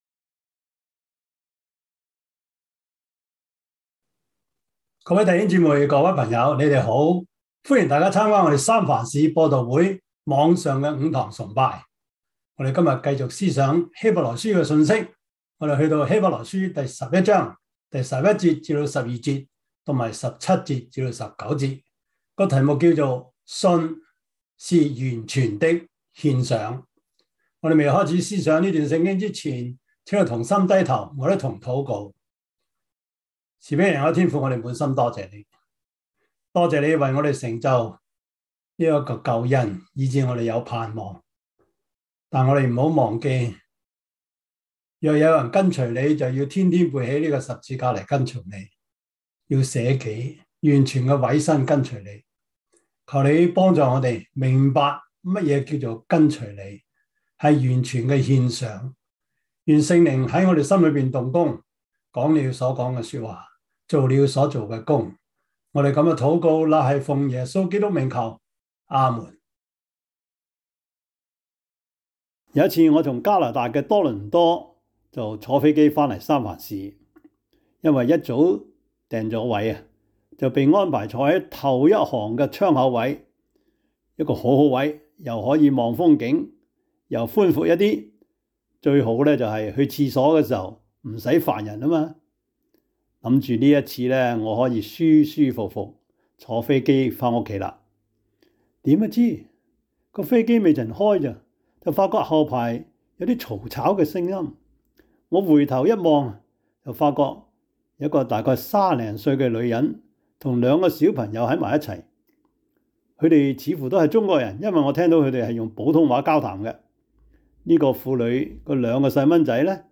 Service Type: 主日崇拜
Topics: 主日證道 « 哀號 第二十六課: 30年代的大復興(2) »